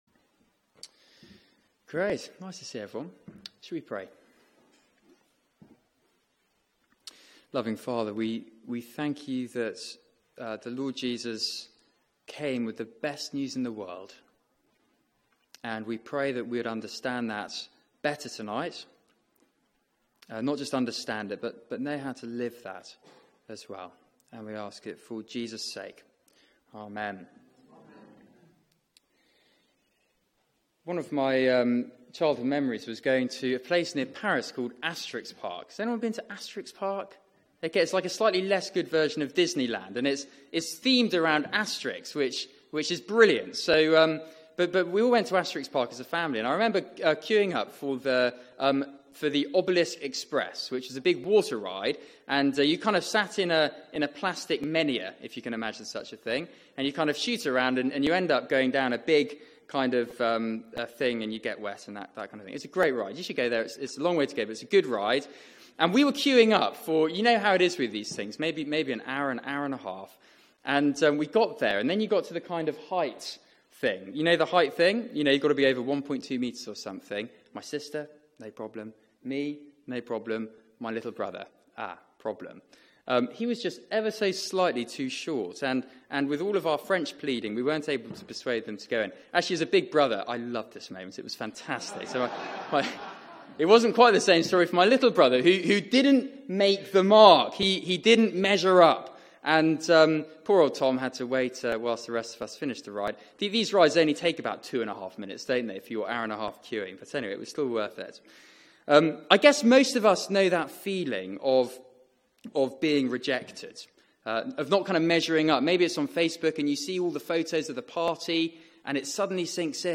Media for 6:30pm Service on Sun 17th Sep 2017 18:30 Speaker
Theme: Justified by faith Sermon